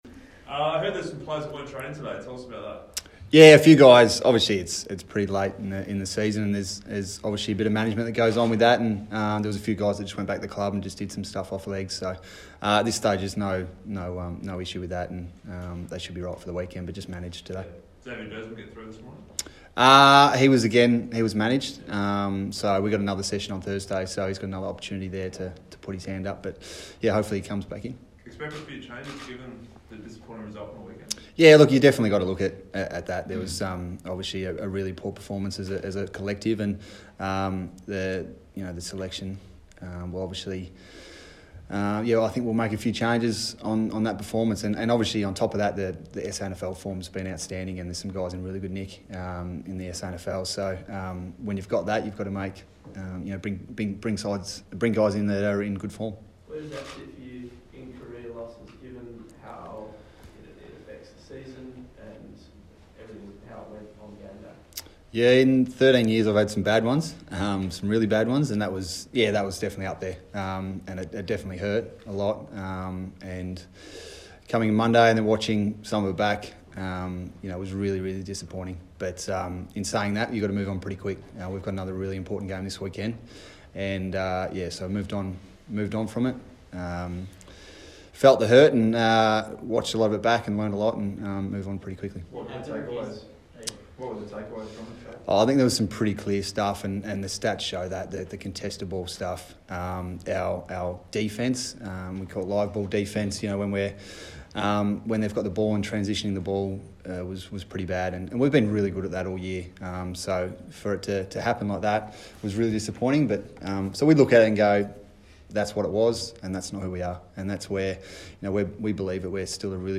Travis Boak press conference - Tuesday 20 August, 2019